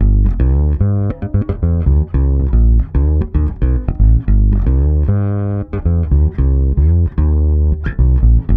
-AL AFRO A.wav